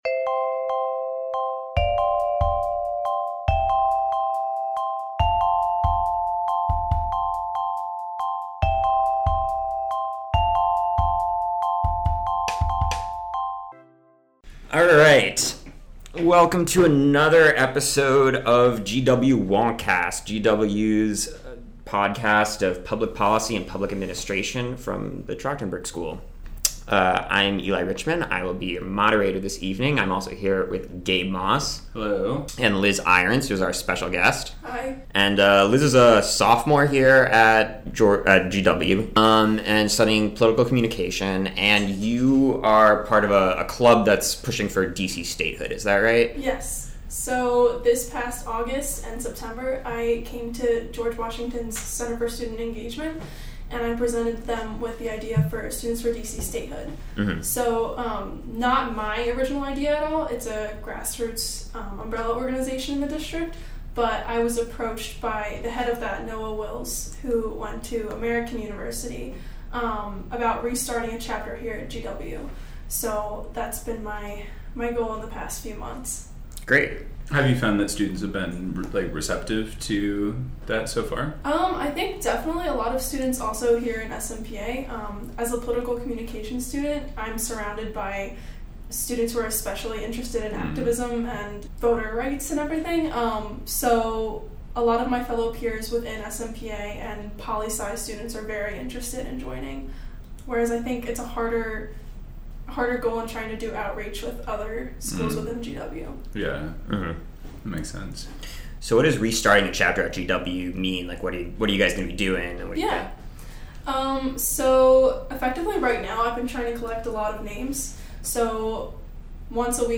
Join us for a lively discussion of the arguments for D.C. statehood, a topic of particular relevance for our D.C. resident listeners.